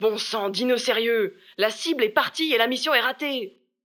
VO_ALL_EVENT_Temps ecoule_03.ogg